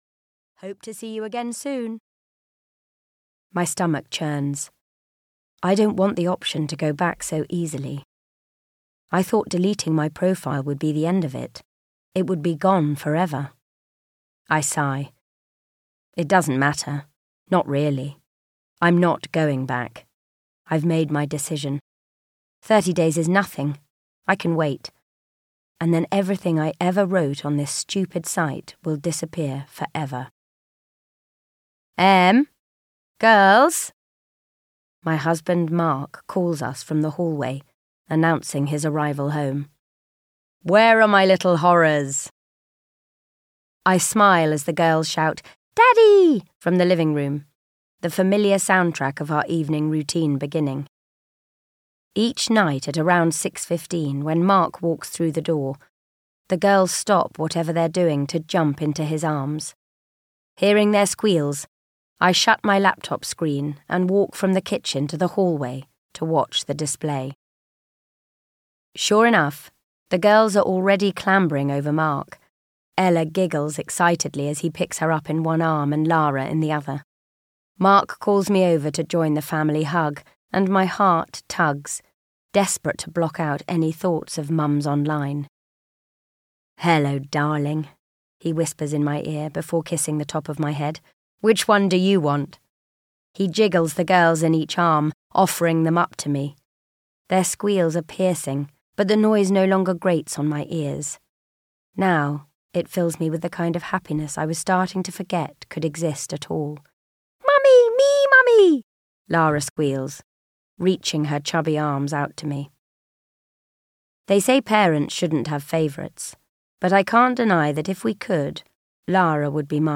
Keep Them Close (EN) audiokniha
Ukázka z knihy